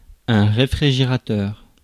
Ääntäminen
Synonyymit frigo frigidaire chambre froide Ääntäminen France: IPA: [ʁe.fʁi.ʒe.ʁa.tœʁ] Haettu sana löytyi näillä lähdekielillä: ranska Käännös Ääninäyte Substantiivit 1. refrigerator US UK 2. fridge UK Suku: m .